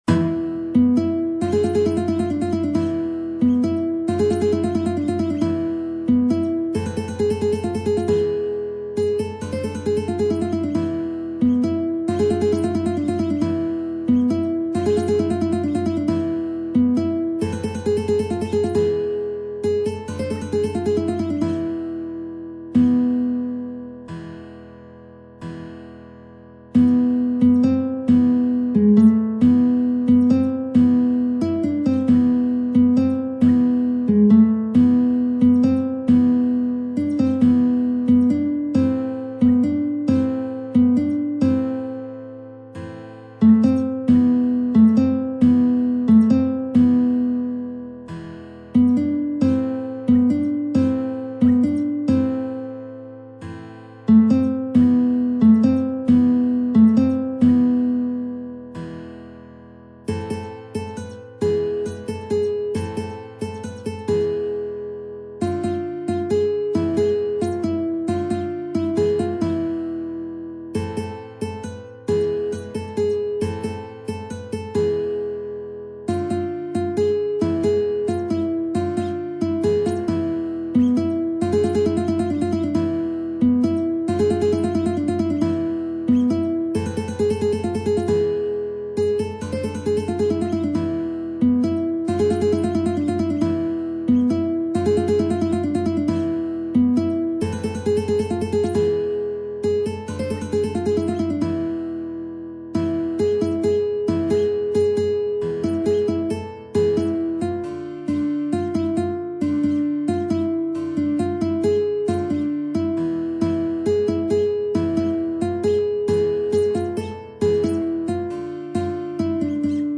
نت ملودی به همراه تبلچر و آکورد